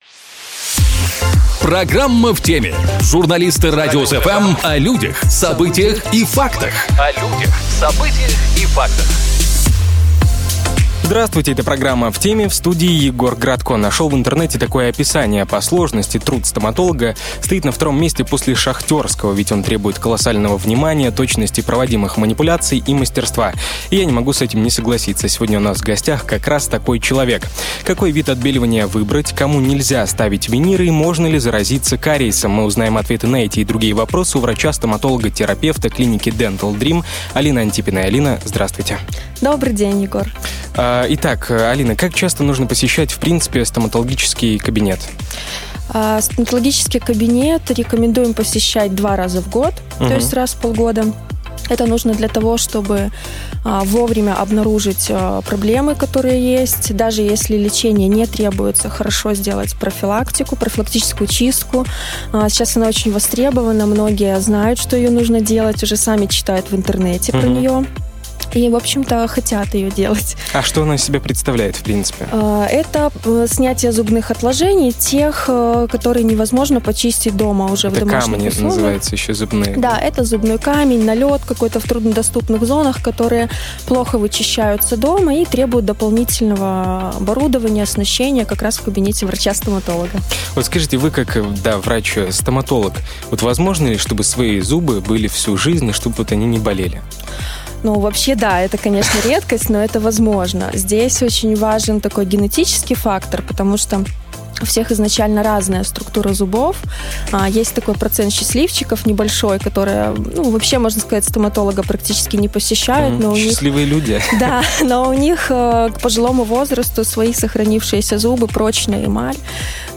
Сегодня у нас в гостях как раз такой человек.